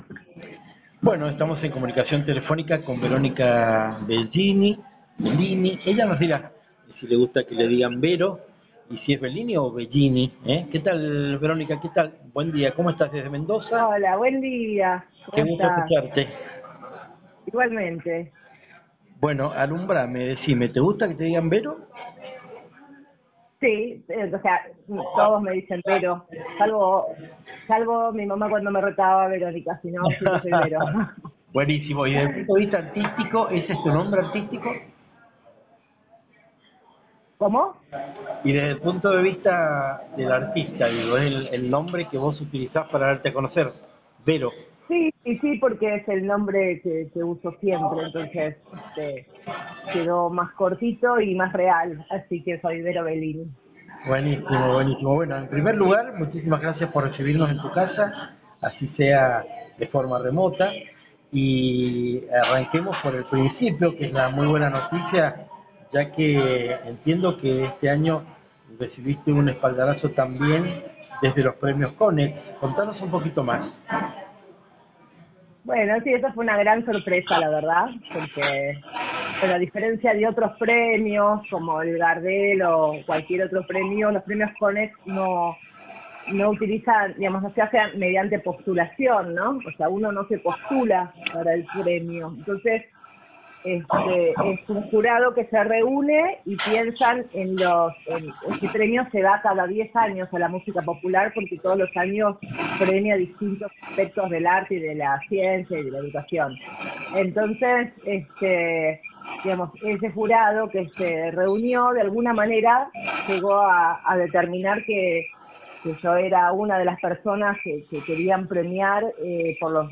Entrevistas Latinocracia